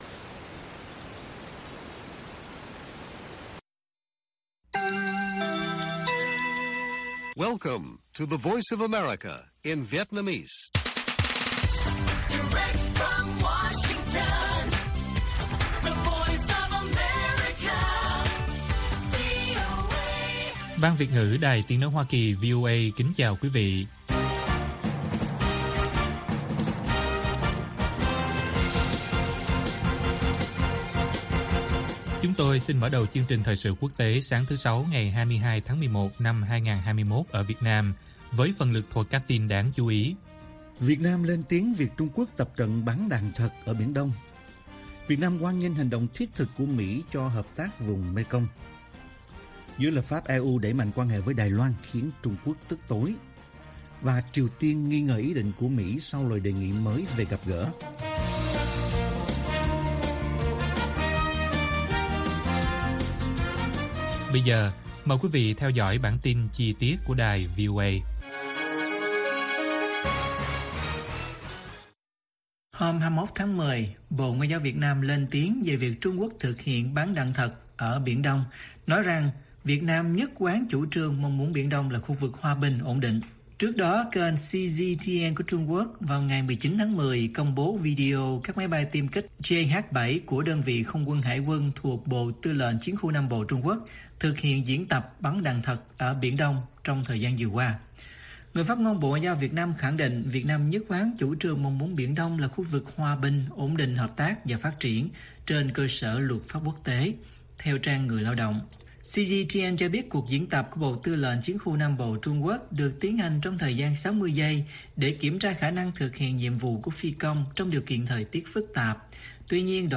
Bản tin VOA ngày 22/10/2021